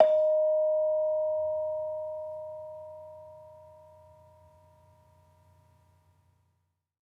Bonang-D#4-f.wav